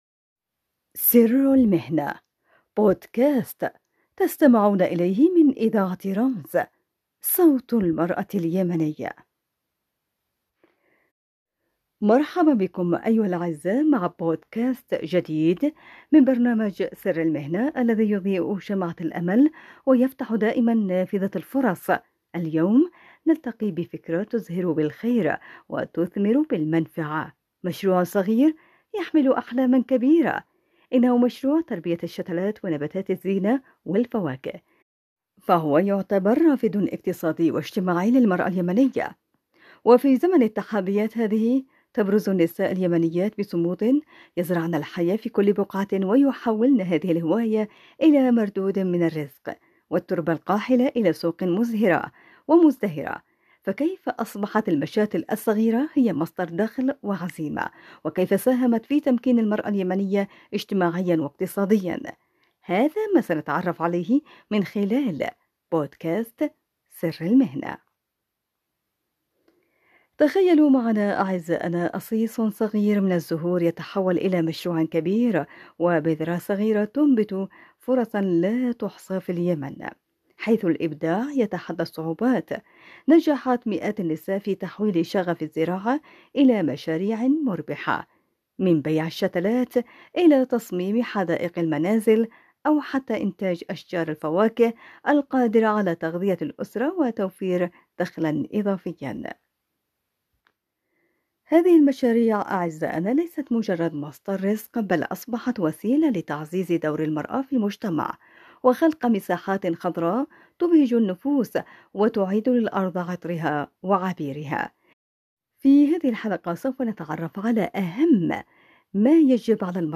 حوار مميز